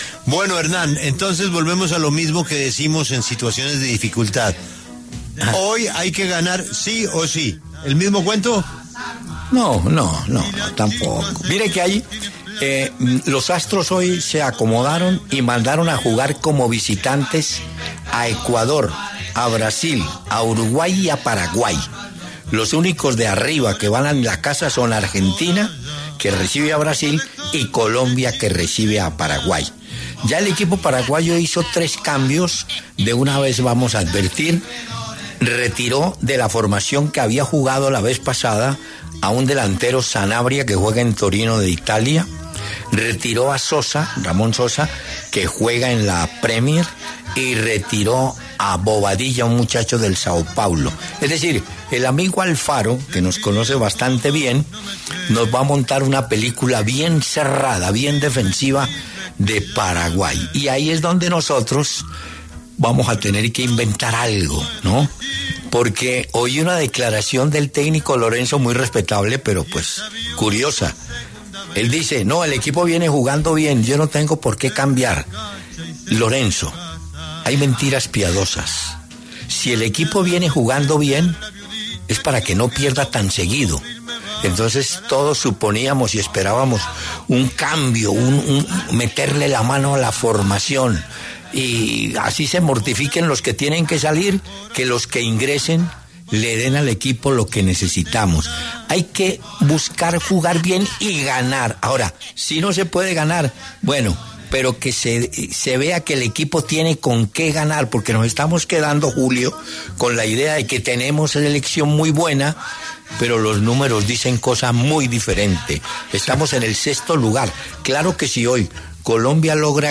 Hernán Peláez, uno de los más reconocidos periodistas deportivos del país, analizó lo que será el partido entre la Selección de Colombia y Paraguay, que se jugará este martes, 25 de marzo, en Estadio Metropolitano de Barranquilla por la jornada 14 de las Eliminatorias Sudamericanas rumbo al Mundial 2026.